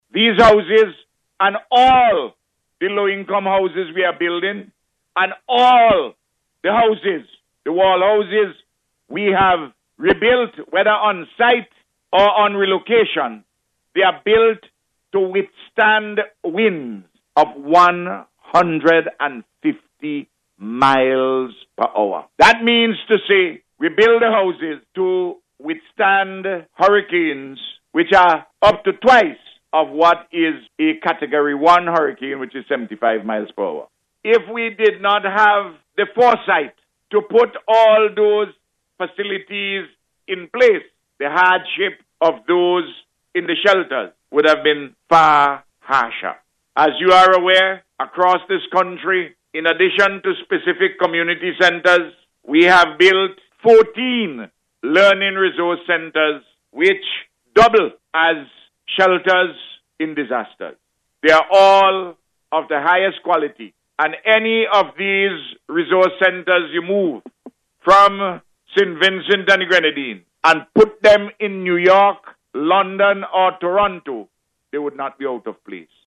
The Prime Minister gave the assurance as he delivered the feature address at the ceremony held in Hope Vermont yesterday, to mark the handover of three houses to affected persons.